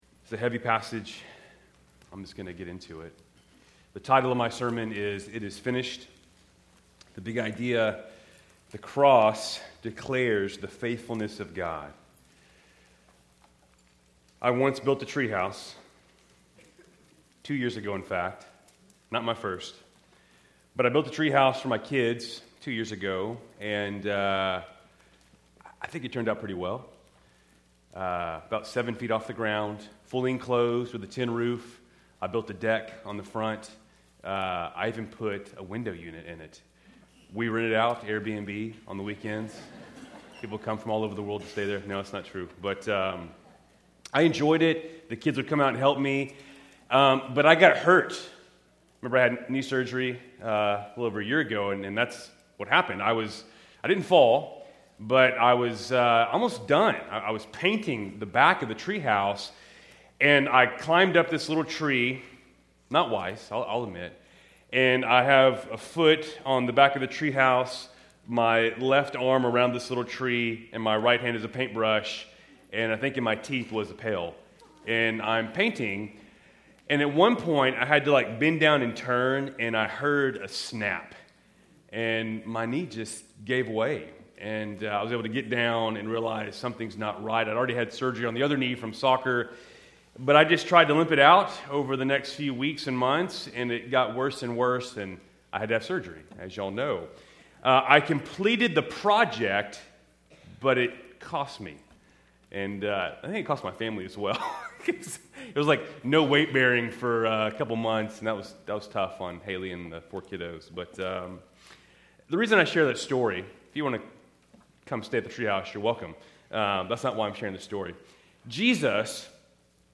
Keltys Worship Service, February 15, 2026